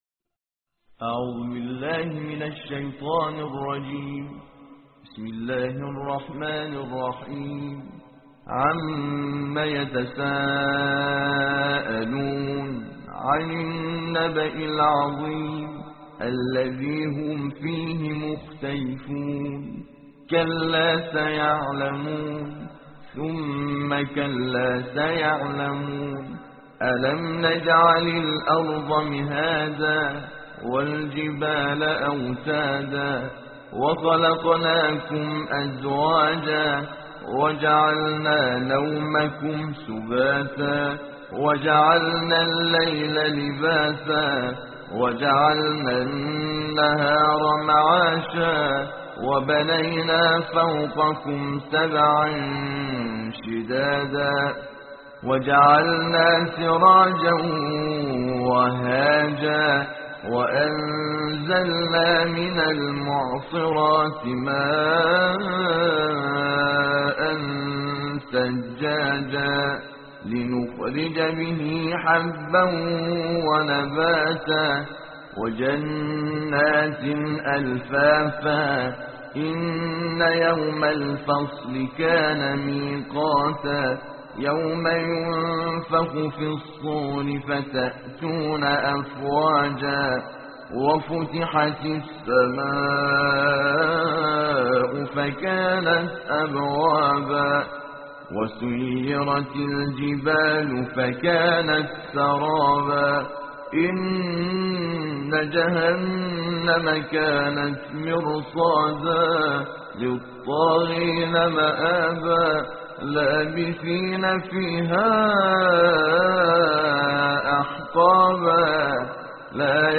صوت/ ترتیل جزء بیست‌ونهم قرآن توسط "حامد شاکرنژاد"